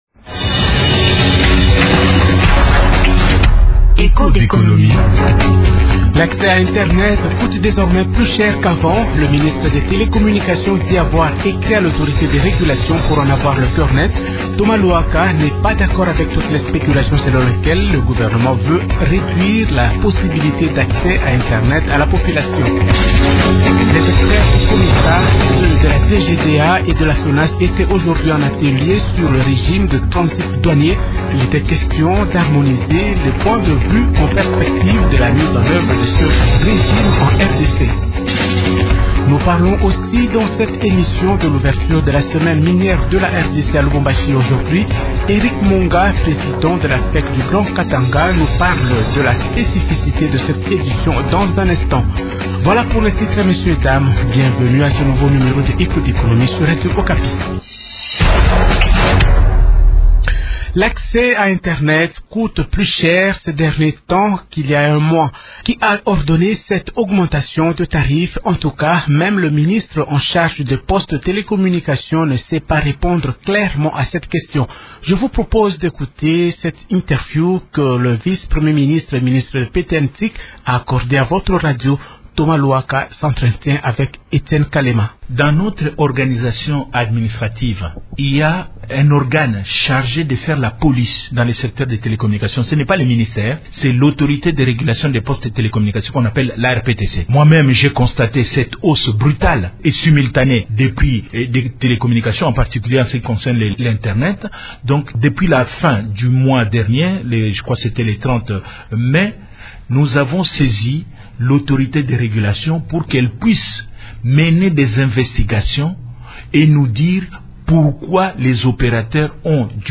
Réaction de Thomas Luhaka sur l’augmentation des prix de la connexion internet
Le gouvernement congolais n’a pas demandé aux opérateurs des télécoms de procéder à cette hausse, a indiqué mardi 7 juin à Radio Okapi, le vice-Premier ministre des PT-NTIC, Thomas Luhaka.